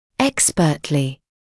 [‘ekspɜːtlɪ][‘экспёːтли]квалифицированно; на высоком уровне (знаний или навыков)